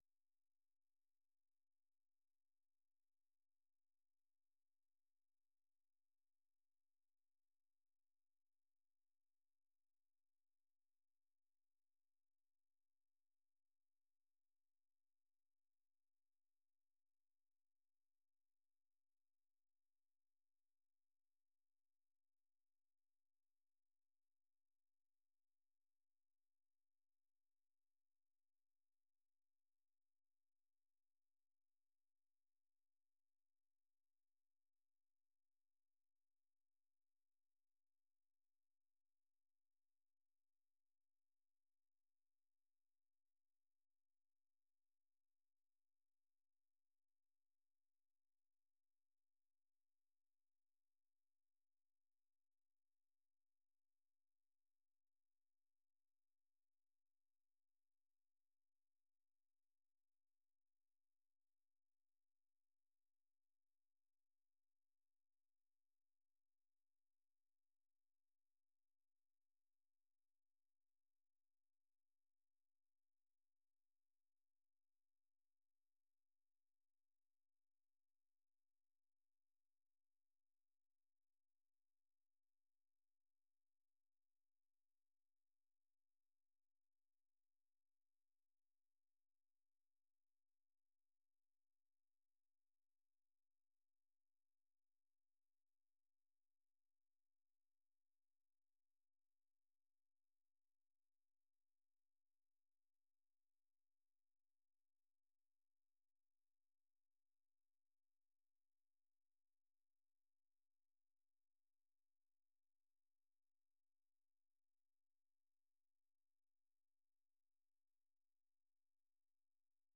The URL has been copied to your clipboard 페이스북으로 공유하기 트위터로 공유하기 No media source currently available 0:00 0:59:58 0:00 생방송 여기는 워싱턴입니다 생방송 여기는 워싱턴입니다 아침 공유 생방송 여기는 워싱턴입니다 아침 share 세계 뉴스와 함께 미국의 모든 것을 소개하는 '생방송 여기는 워싱턴입니다', 아침 방송입니다.